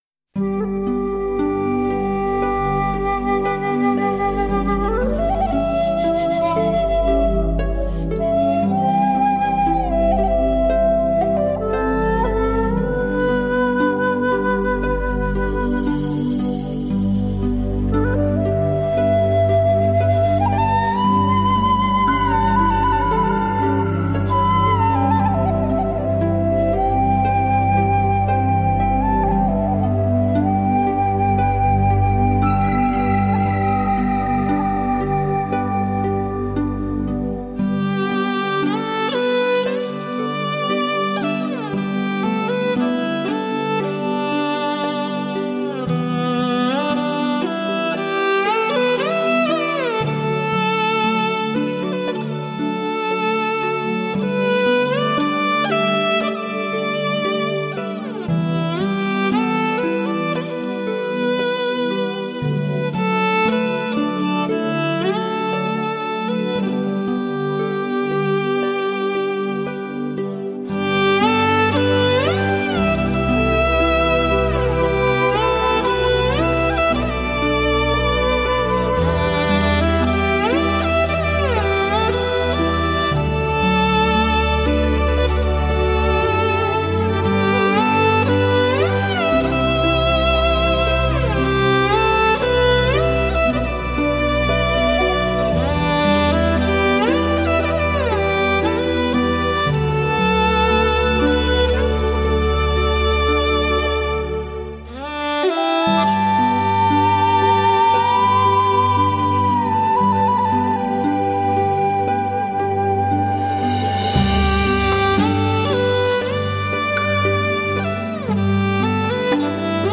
马头琴
那暗含感伤又心潮跌宕的旋律，即使在一些欢快的节奏下，也仍能让我们听出草原民族的内心情愁。
马头琴的音色细腻如小提琴，醇厚如中提琴，深情如大提琴。